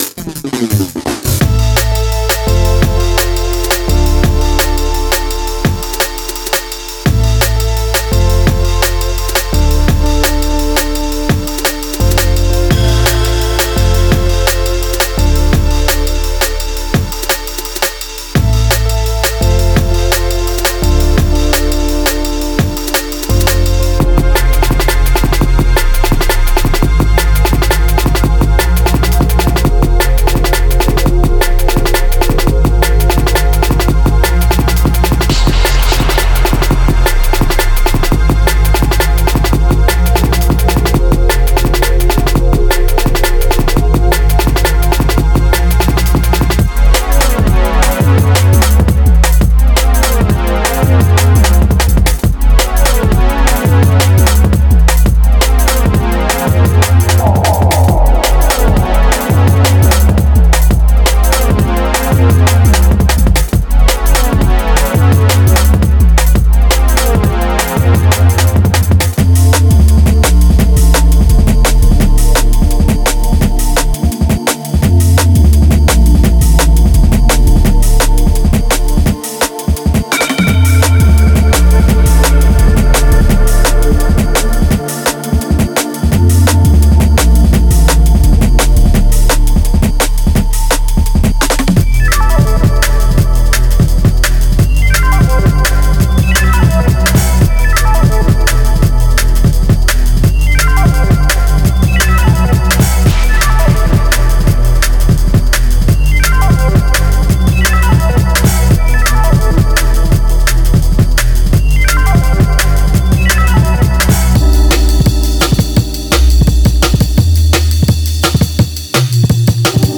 Genre:Jungle
デモサウンドはコチラ↓
90 Breaks
16 Top Loops
15 Perc Loops
7 Bass Loops